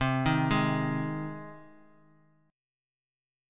Cm#5 chord